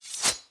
Sword Attack 3.ogg